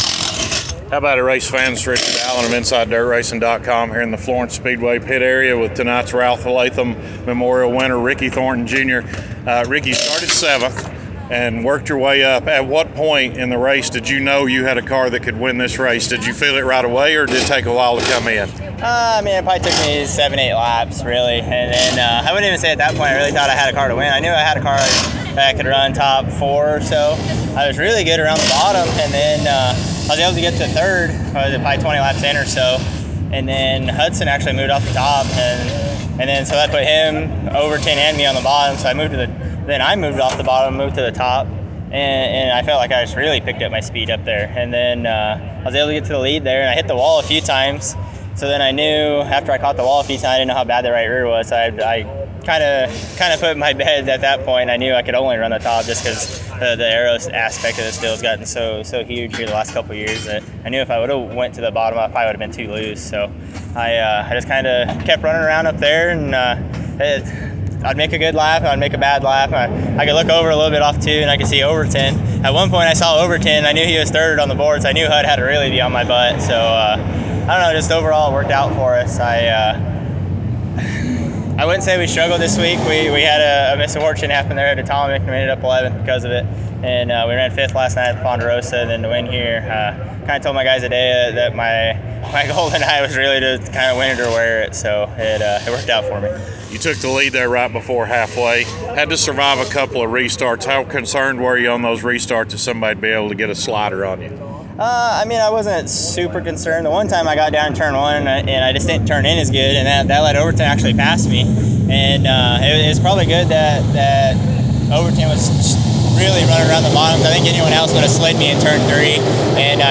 post-race interview